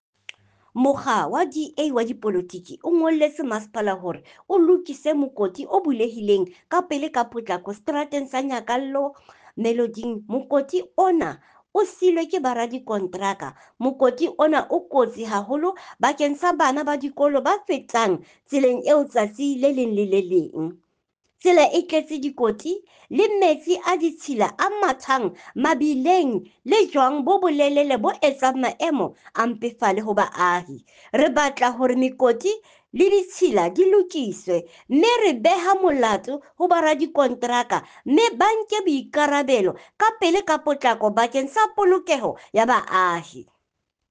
Sesotho soundbites by Cllr Florence Bernado and Afrikaans soundbite by Cllr Jessica Nel.